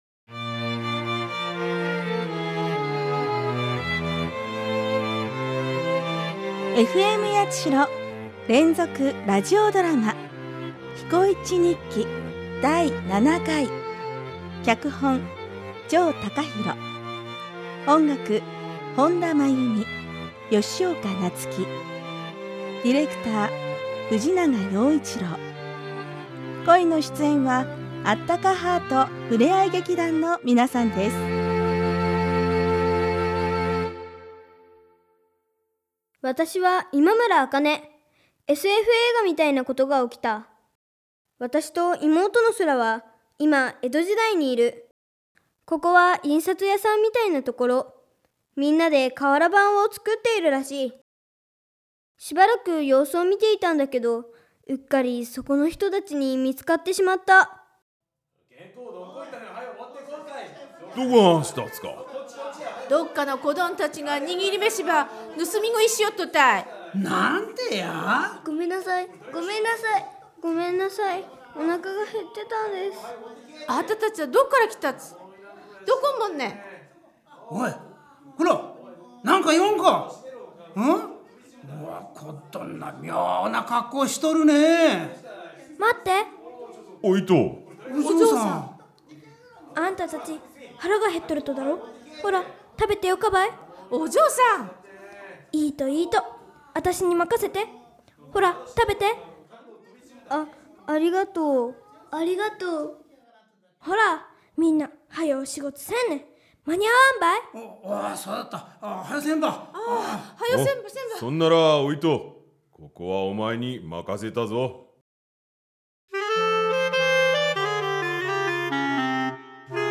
Radio Drama